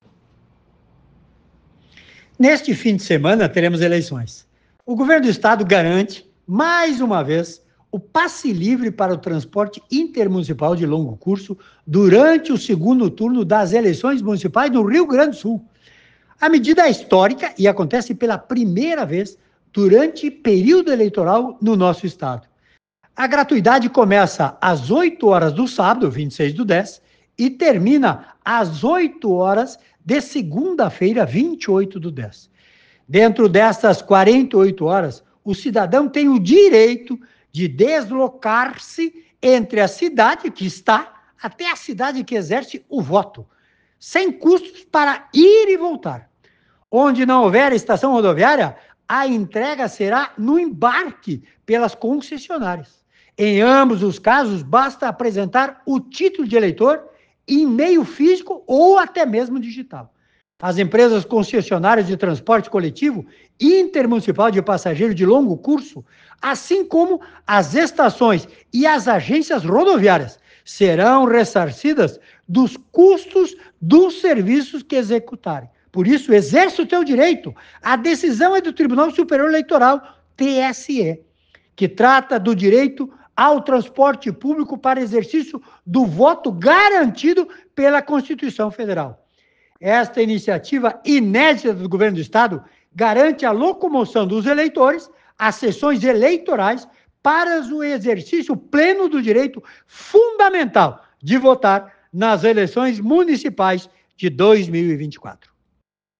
Secretário Juvir Costella fala sobre passe livre para o segundo turno